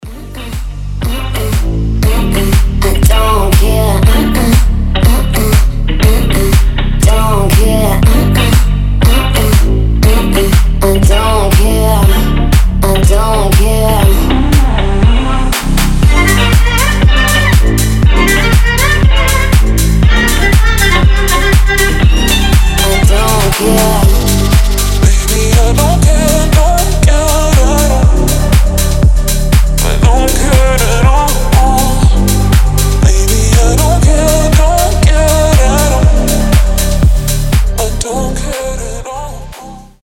• Качество: 320, Stereo
deep house
Electronic
EDM
скрипка
басы
чувственные
восточные
красивый женский голос